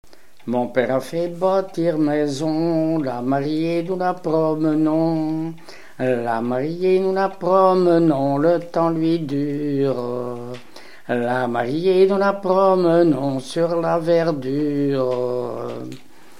marche de cortège de noce
Pièce musicale inédite